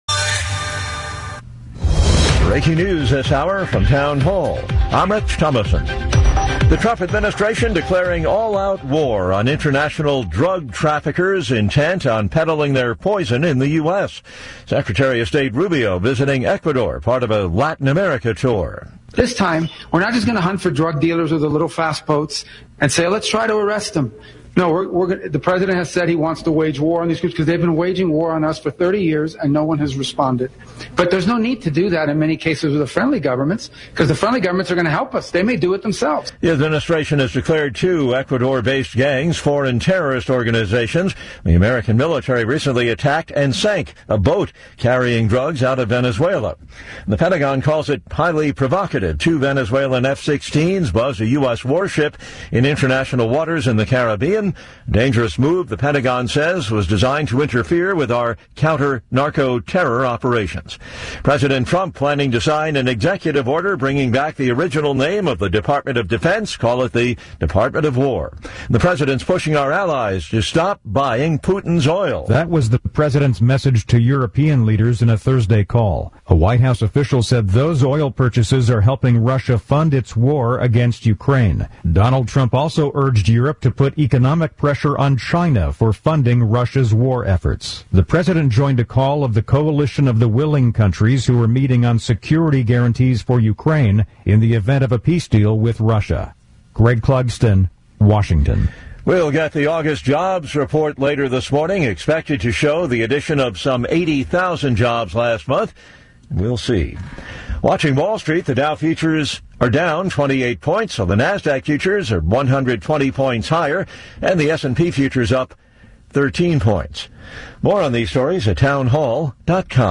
guest host Del. Nino Mangione